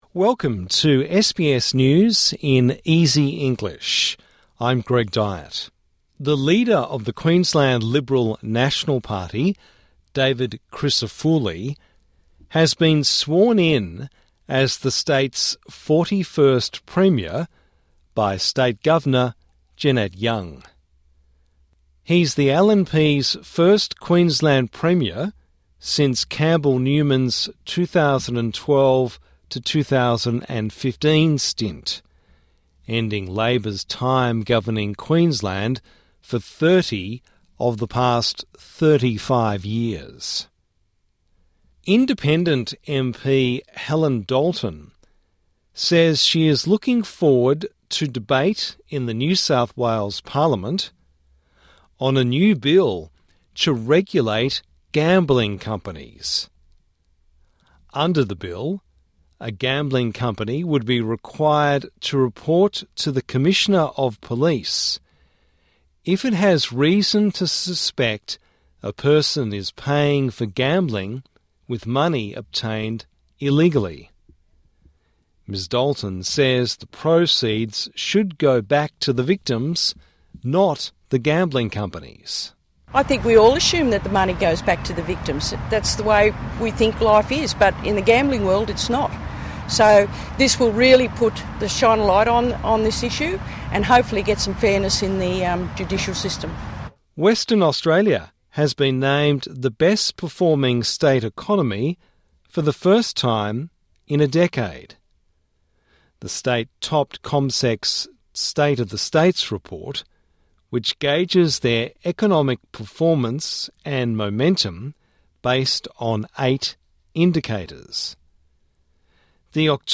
A daily five minute news wrap for English learners and people with disability.